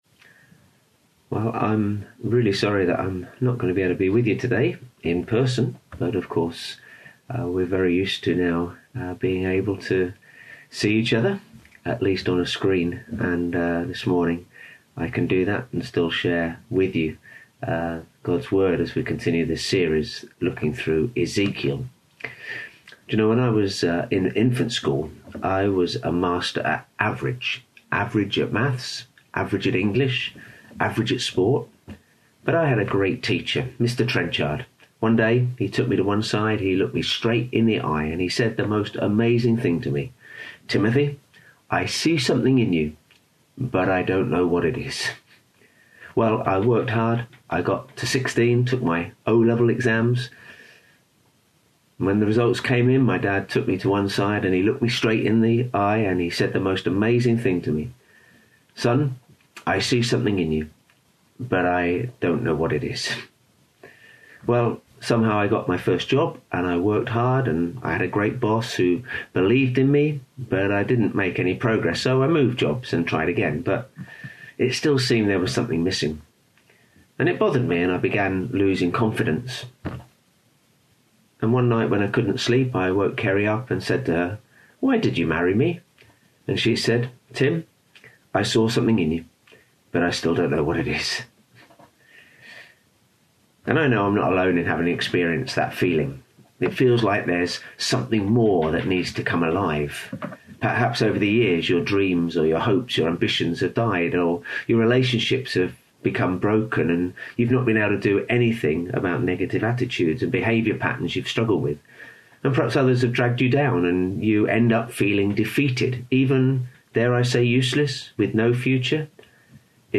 Today's sermon is based on Ezekiel 37